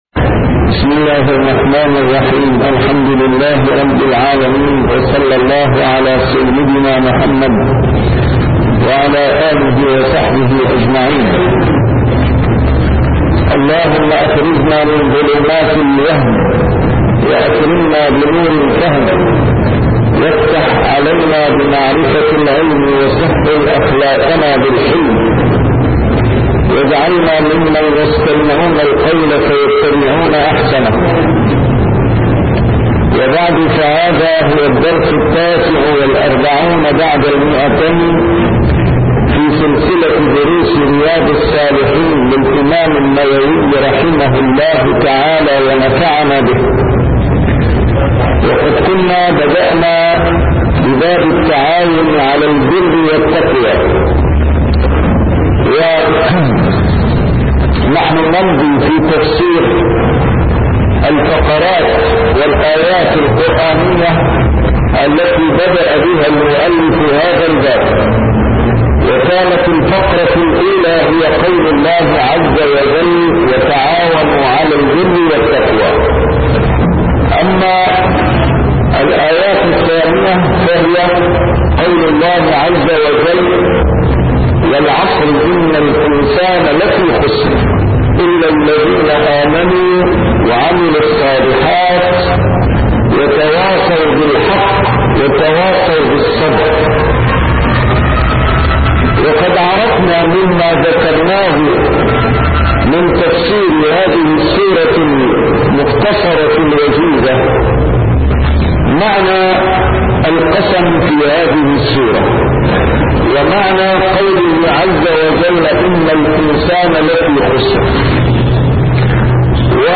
شرح كتاب رياض الصالحين - A MARTYR SCHOLAR: IMAM MUHAMMAD SAEED RAMADAN AL-BOUTI - الدروس العلمية - علوم الحديث الشريف - 249- شرح رياض الصالحين: التّعاون على البرّ والتّقوى